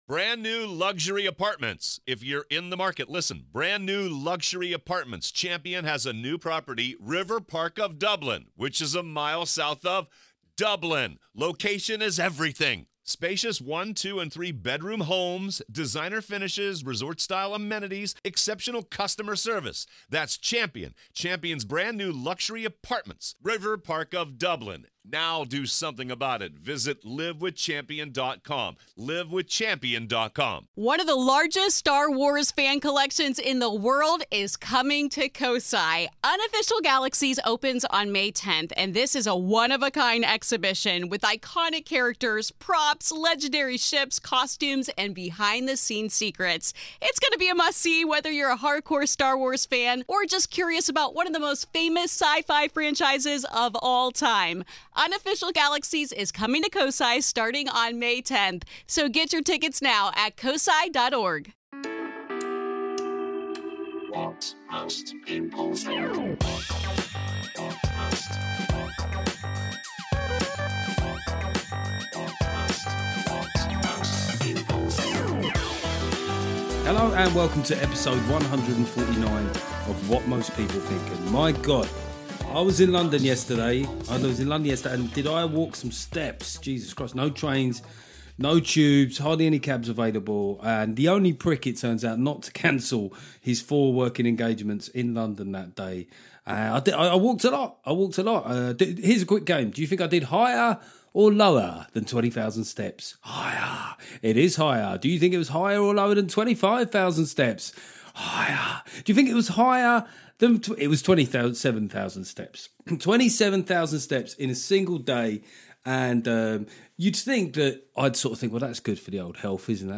Solo show this week as we take a deep dive into the rail strikes and reformed 'firm' member Mick Lynch.